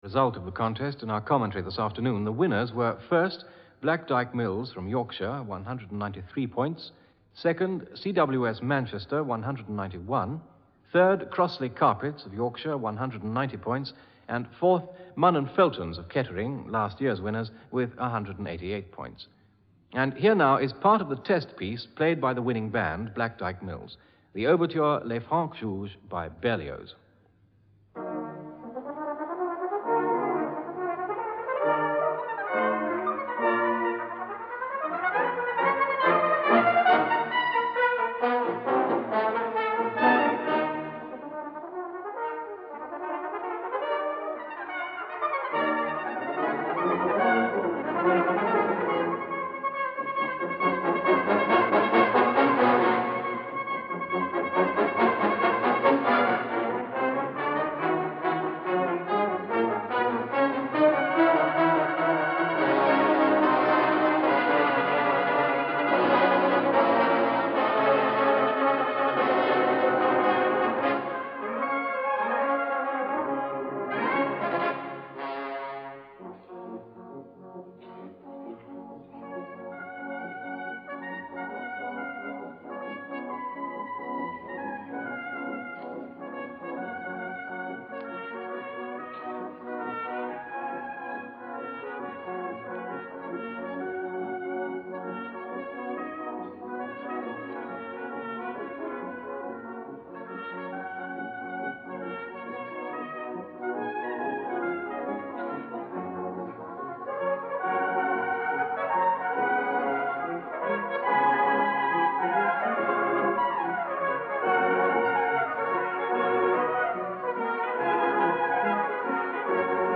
Dating from 1855 this is probably the World’s most famous brass band originally sponsored by John Foster’s and Sons of Queensbury , West Yorkshire woollen mills fame.
…. and now for something completely different – testing mp3 format of a live performance.
and yes that is Harry Mortimer doing the commentary.
Later that evening following a concert by the Massed Brass Bands a further portion of the test piece was broadcast :